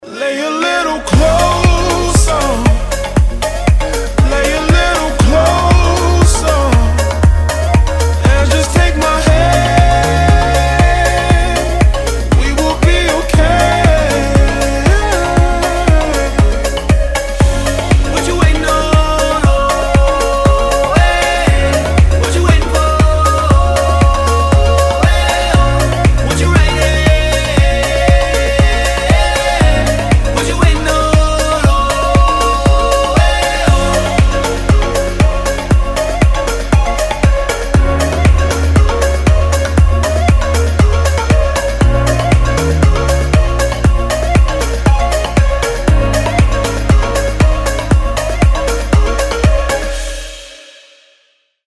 Танцевальные
поп, Electronic